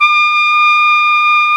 Index of /90_sSampleCDs/Roland L-CDX-03 Disk 2/BRS_Piccolo Tpt/BRS_Picc.Tp 1